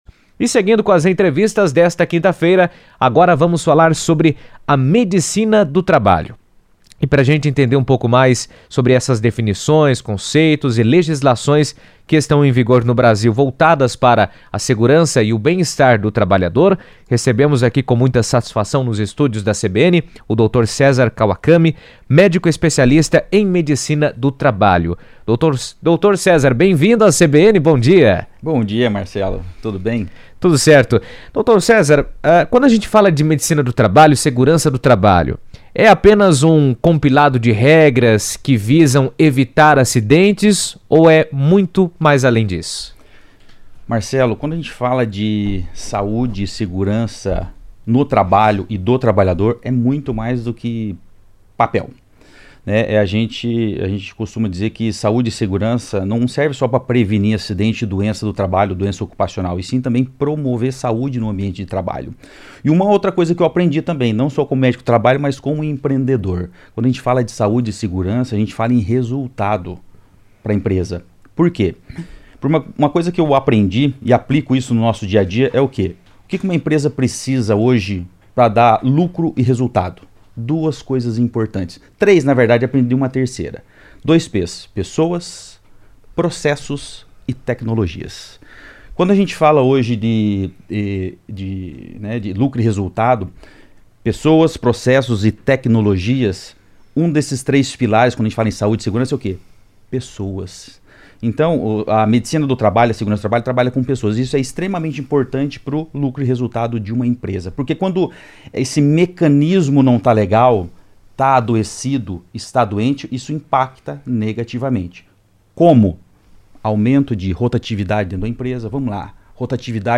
A Medicina do Trabalho desempenha um papel fundamental na prevenção de doenças e acidentes, reunindo ações como exames ocupacionais, gestão da saúde corporativa, programas obrigatórios e medidas de segurança para proteger colaboradores e garantir a conformidade legal das empresas. Em entrevista à CBN